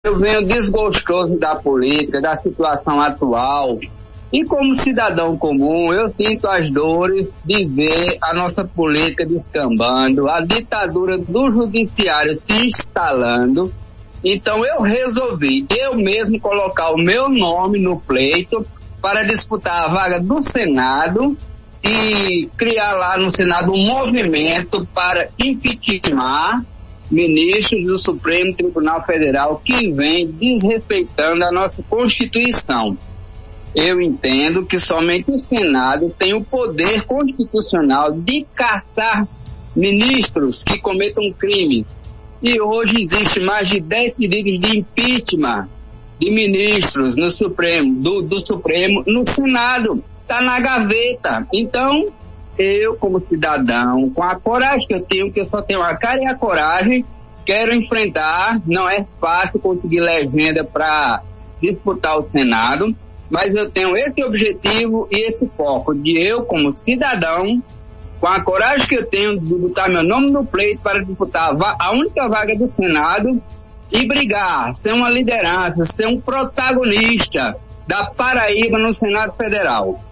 Nesta quinta-feira (25), em entrevista ao programa Arapuan Verdade, o neo-candidato deu o tom de sua futura campanha e possível mandato e avisou que entra para combater o ativismo no judiciário.